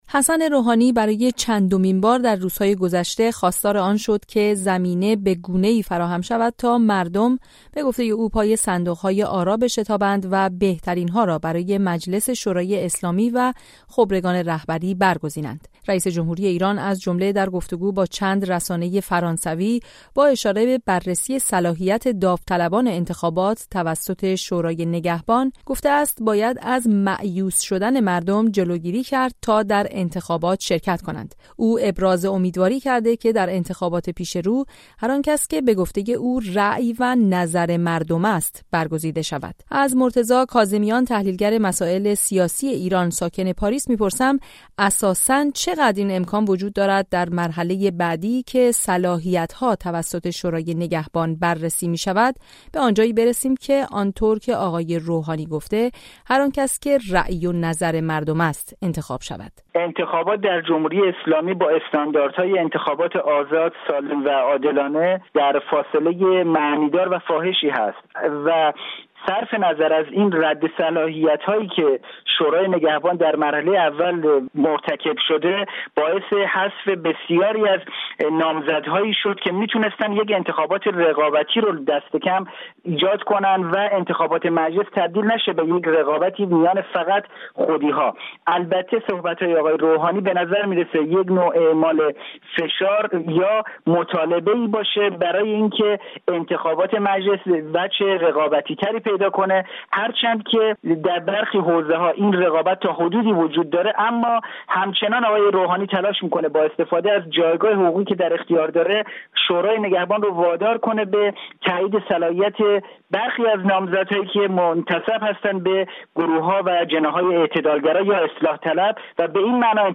گزارش‌های رادیویی